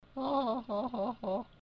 gaylaugh